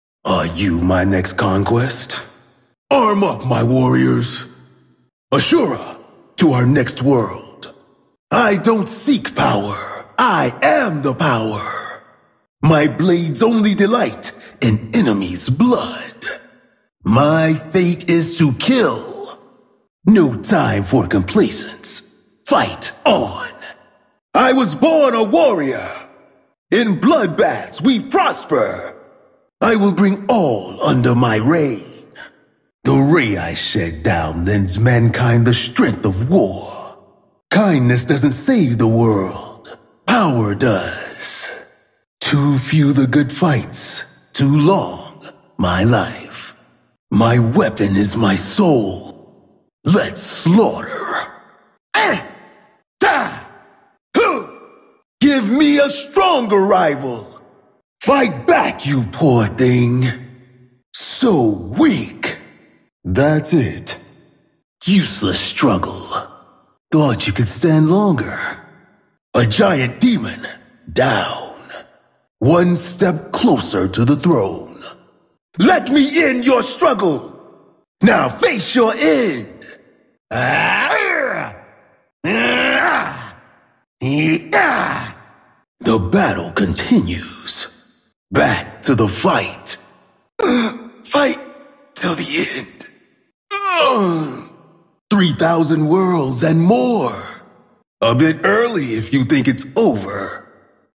God of War Mp3 Sound Effect Martis - God of War Voice Lines.